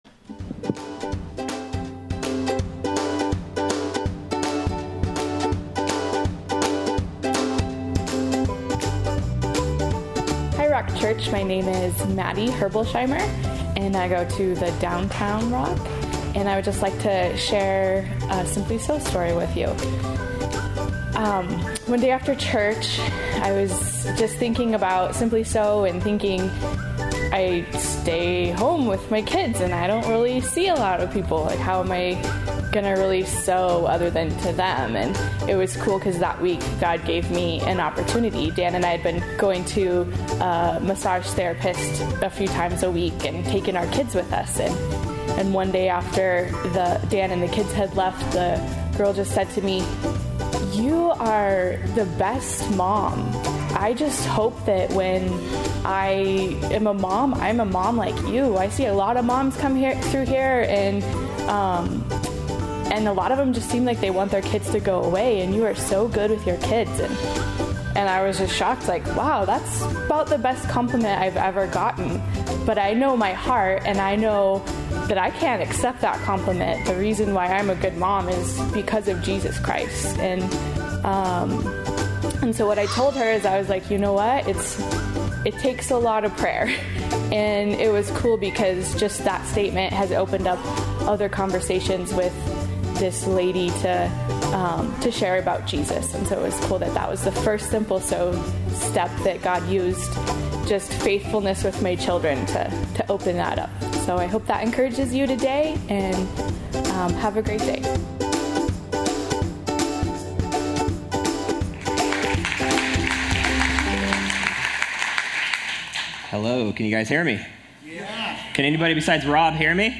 A study through the book of Acts.